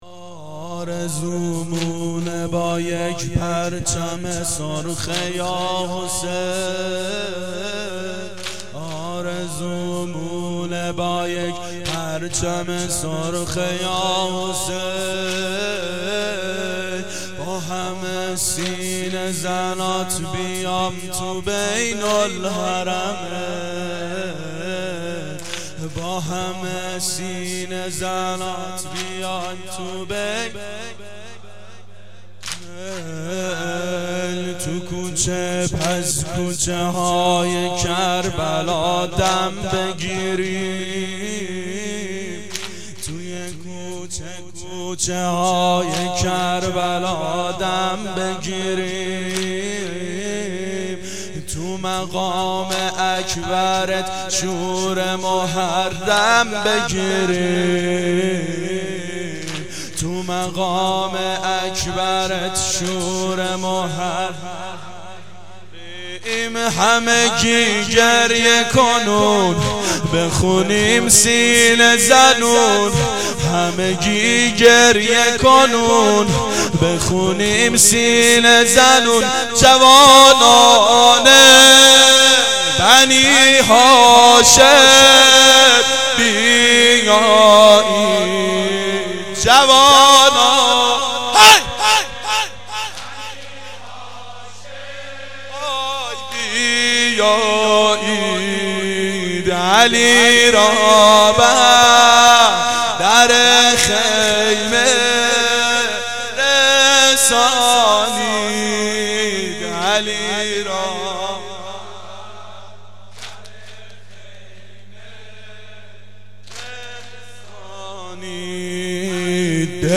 شب اول محرم 89 گلزار شهدای شهر اژیه